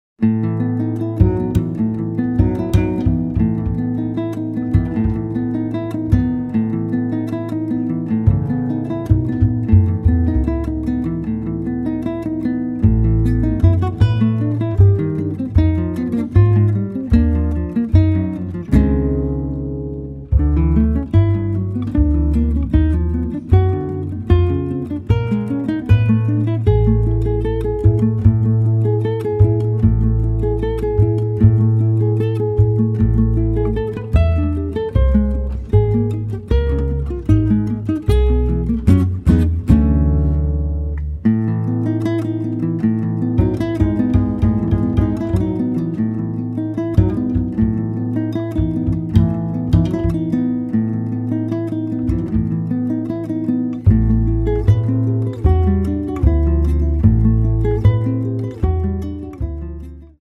acoustic guitar
acoustic bass